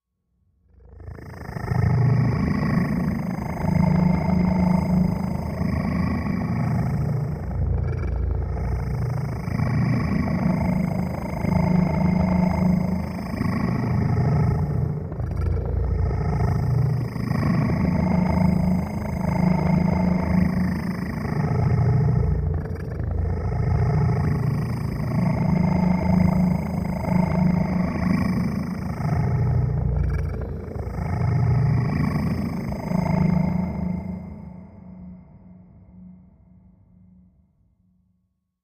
Movement Low Electric Voice Shimmers Back And Forth